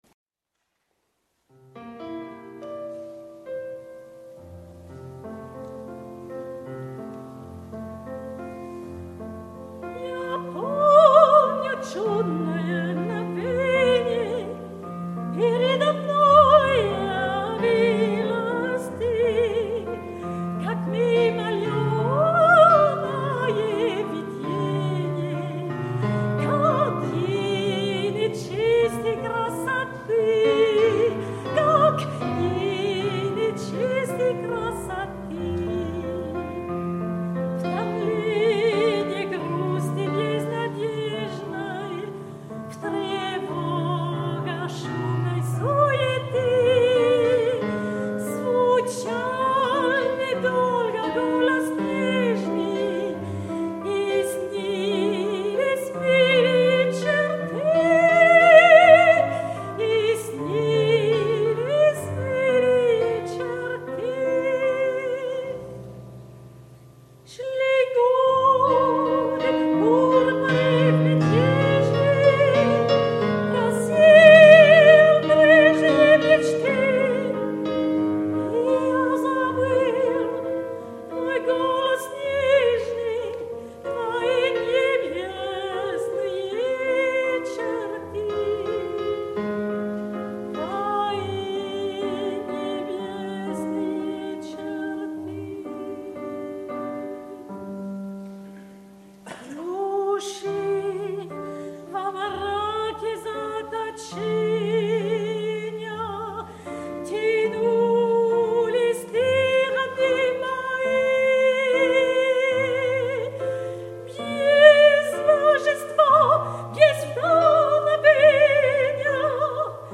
2017-11-19 Concert de St-André-sur-Sèvre